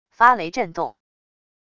发雷振动wav音频